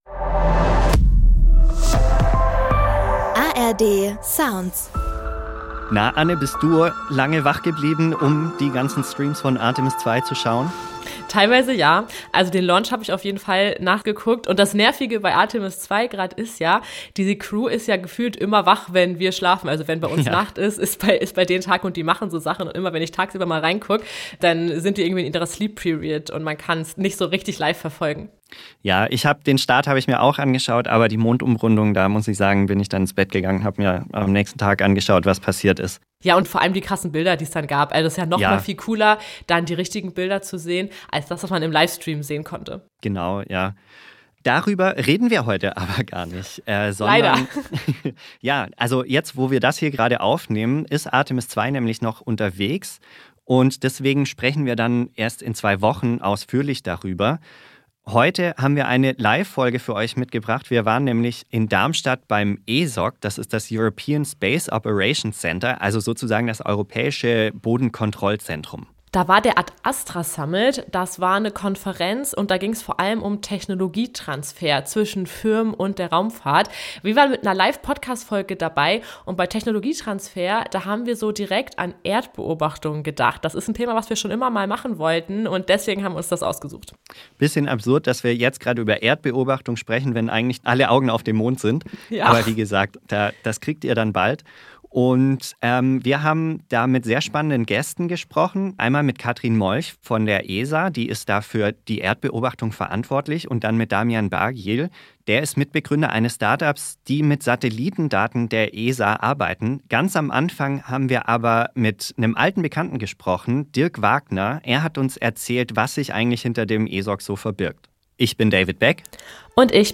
Die ESA macht das mit ihrem Erdbeobachtungsprogramm Copernicus - und auch andere Weltraumorganisationen haben entsprechende Satelliten im All. In dieser Live-Folge vom Ad Astra Summit am ESOC in Darmstadt geht es nicht nur darum, was diese Satelliten alles an Daten liefern, sondern auch um ein Beispiel, wie Unternehmen sich diese größtenteils kostenlos zur Verfügung stehenden Daten zunutze machen können.